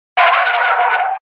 Звуки тормоза автомобиля
На этой странице собрана коллекция звуков тормозов автомобилей: от резкого визга шин до плавного скрежета.